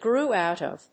grew+out+of.mp3